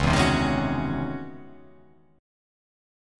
C#mM11 chord